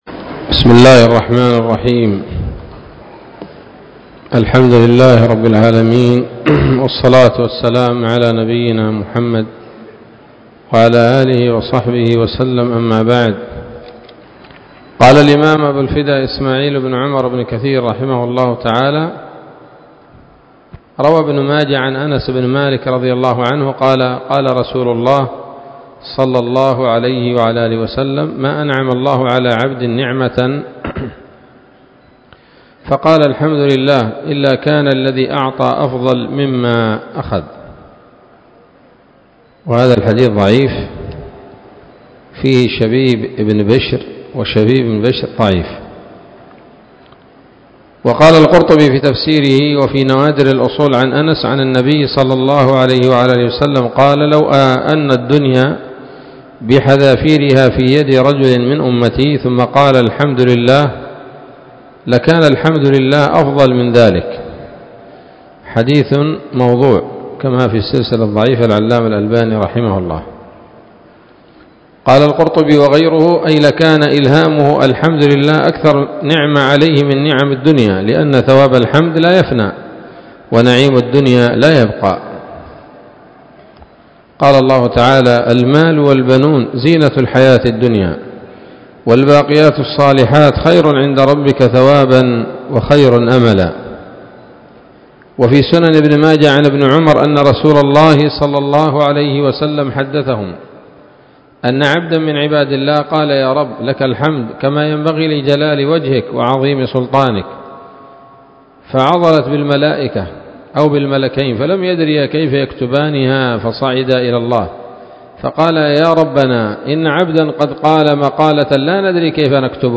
الدرس الخامس عشر من سورة الفاتحة من تفسير ابن كثير رحمه الله تعالى